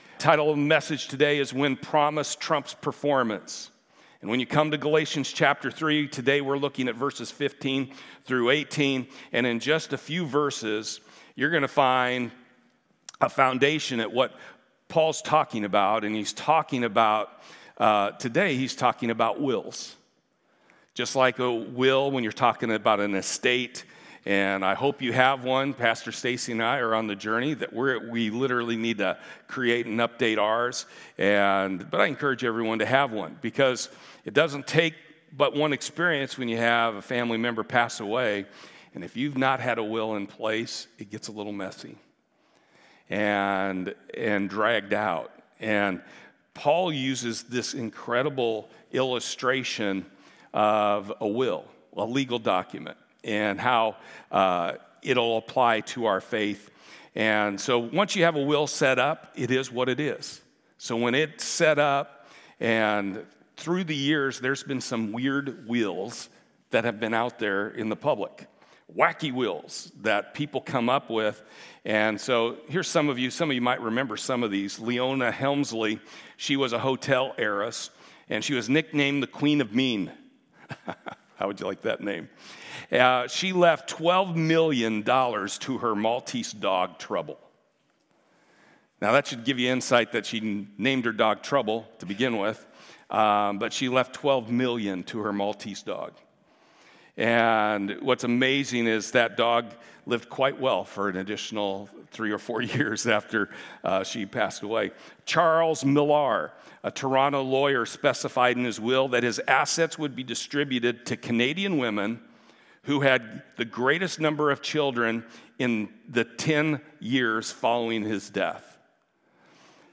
Series: The Book of Galatians Service Type: Sunday